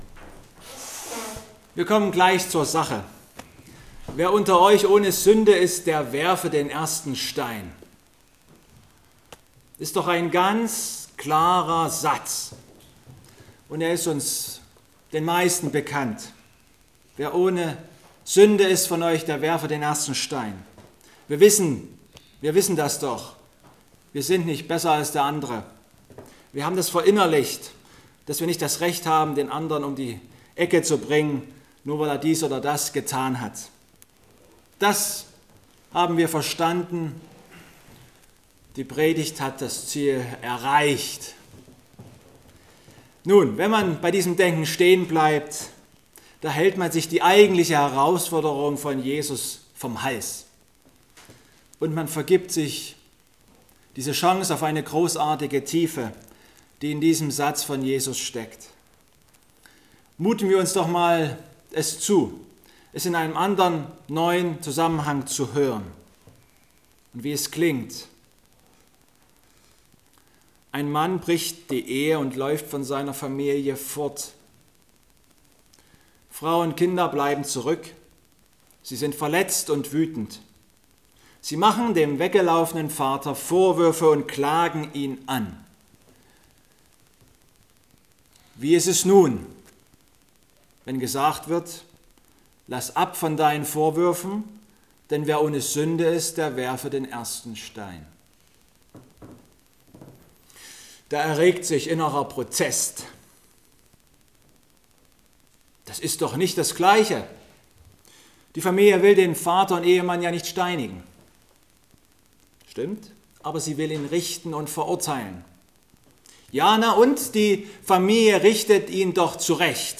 Johannes 8,3-11 Gottesdienstart: Abendmahlsgottesdienst Gemeinde versammelt sich.